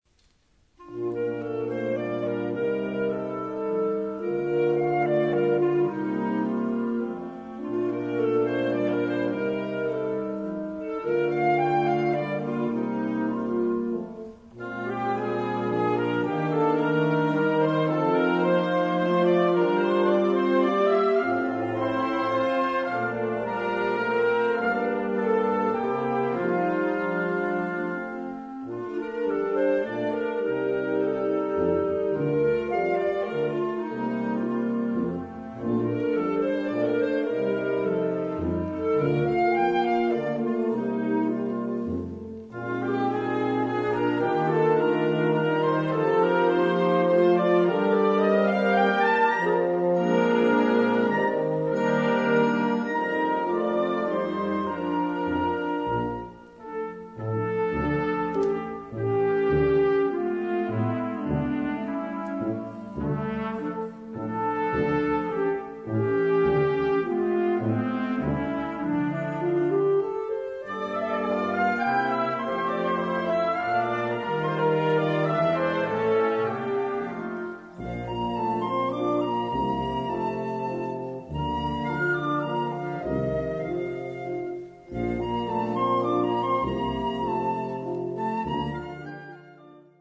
Kategorie Blasorchester/HaFaBra
Unterkategorie Konzertmusik
2 Allegro 1:54